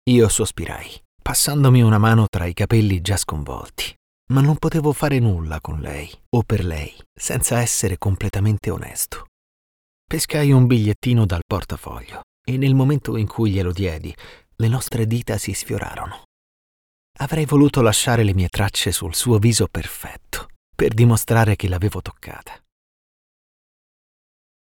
Male
Bright, Engaging, Friendly, Versatile, Authoritative, Character
Microphone: Neumann TLM 103, Universal Audio Sphere Dlx